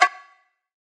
DDW4 PERC 8.wav